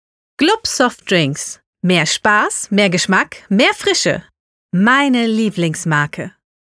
Professionelle Studiosprecherin.